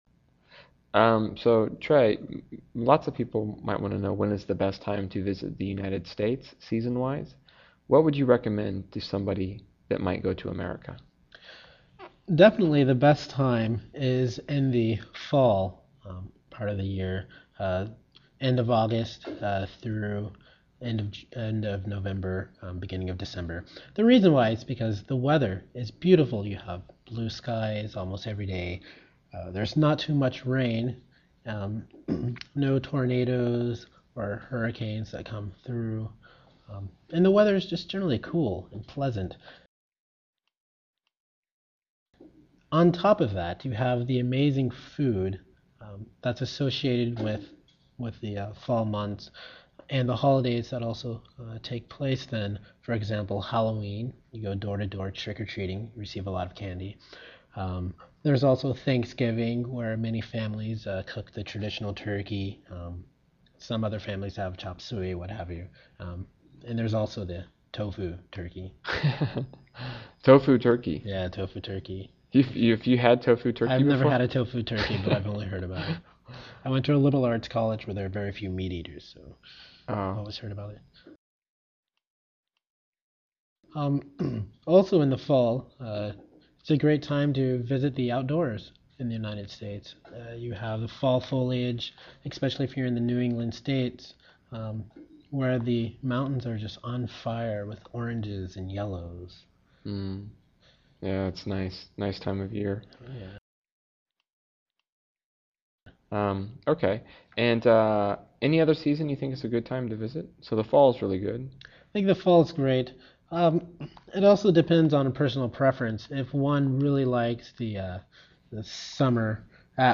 英语访谈对话 527 U.S. Travel Tips 听力文件下载—在线英语听力室
在线英语听力室英语访谈对话 527 U.S. Travel Tips的听力文件下载,英语访谈对话搜集了各个话题访谈对话，让你在听对话的同时轻松学习英语。